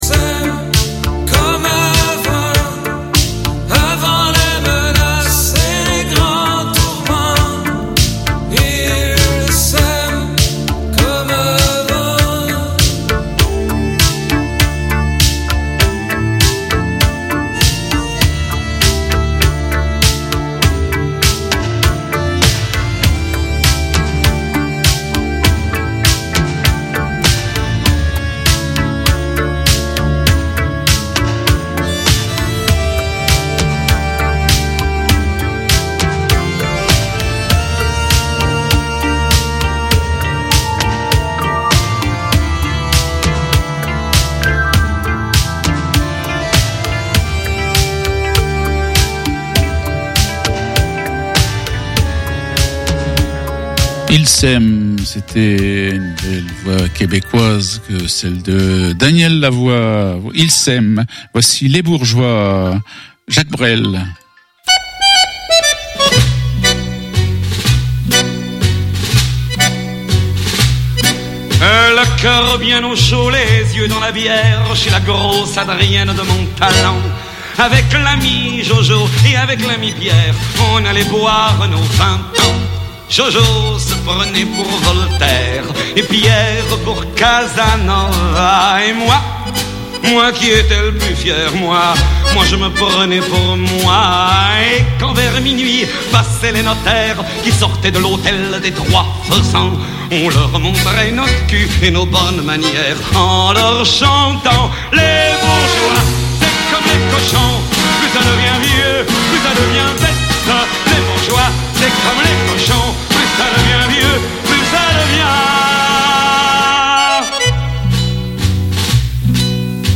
Magazine musical sur les années 70/80. Dédicaces locales.